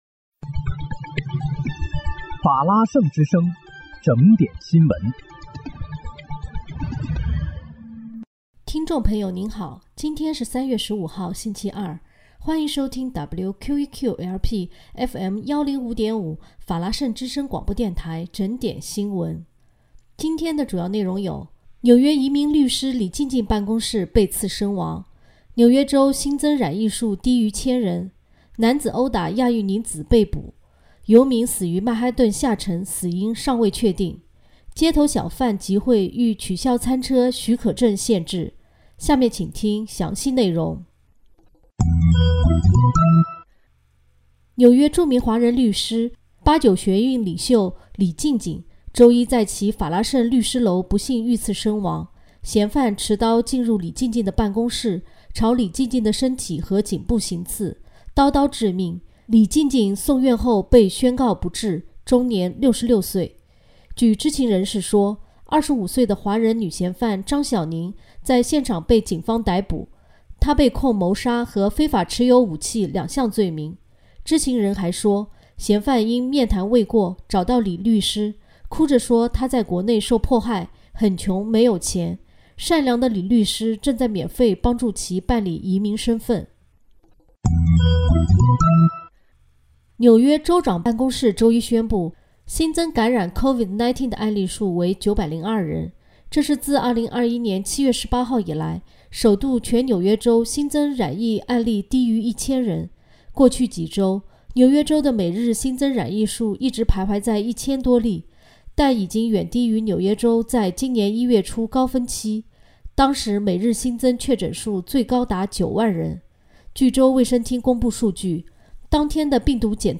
3月15日（星期二）纽约整点新闻
听众朋友您好！今天是3月15号，星期二，欢迎收听WQEQ-LP FM105.5法拉盛之声广播电台整点新闻。